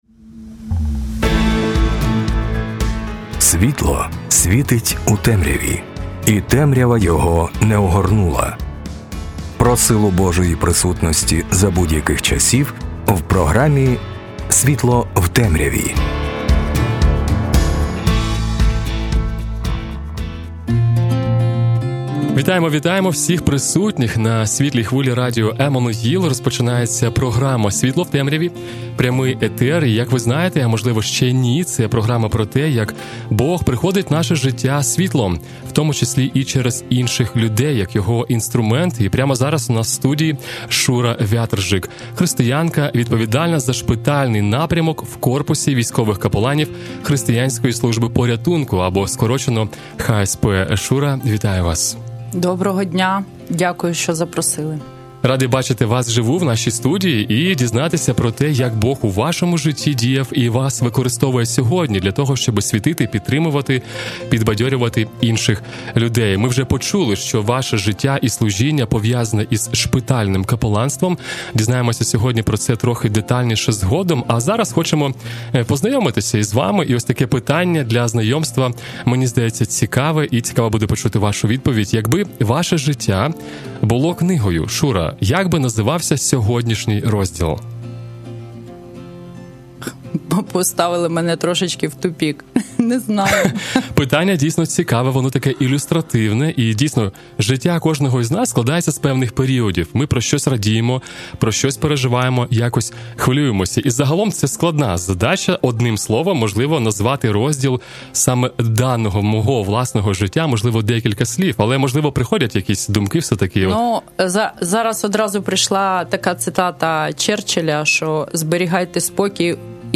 В інтерв'ю піднімаються важливі питання допомоги та служіння військовим та їх родинам, особливо для військових-ветеранів.